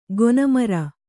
♪ gona mara